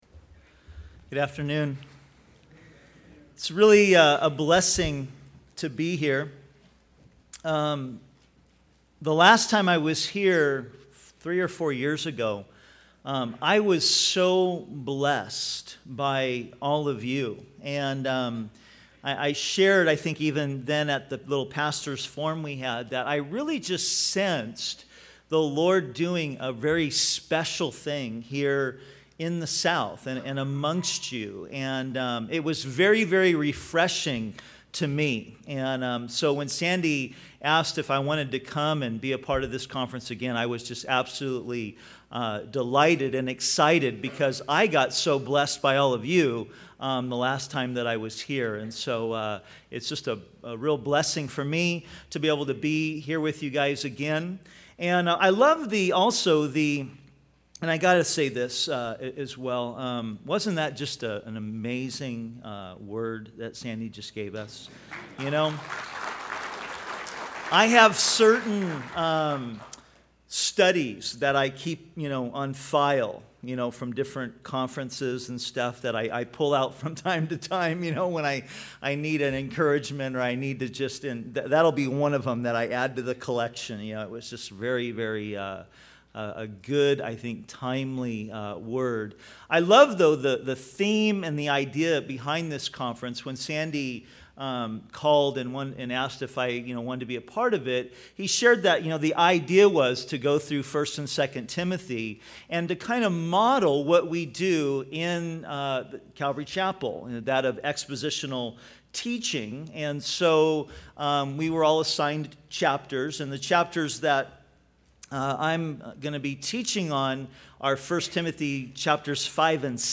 2007 Home » Sermons » Session 2 Share Facebook Twitter LinkedIn Email Topics